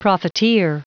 Prononciation du mot profiteer en anglais (fichier audio)
Prononciation du mot : profiteer